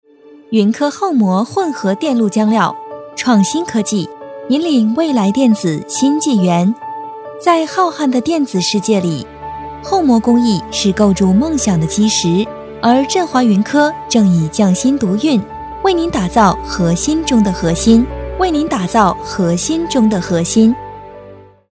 女277-专题-云科
女277角色广告专题 v277
女277-专题-云科.mp3